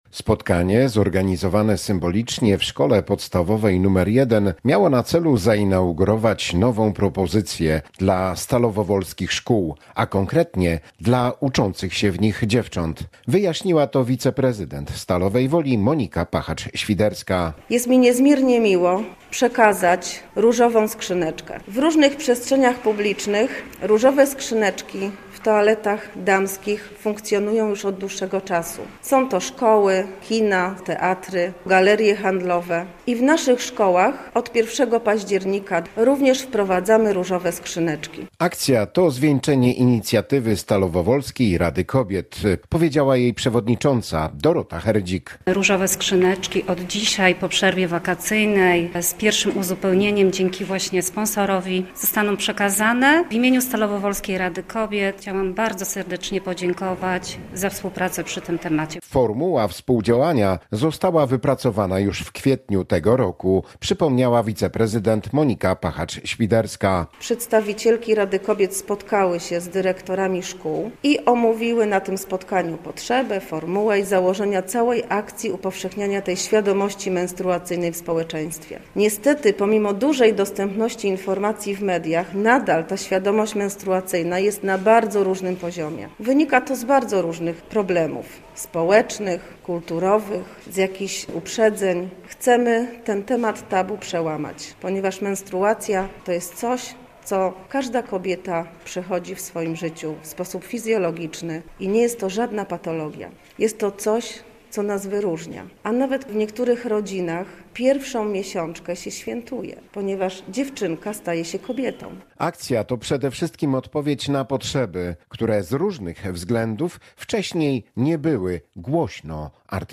Konferencja promująca akcję odbyła się w Szkole Podstawowej nr 1 w Stalowej Woli
Relacja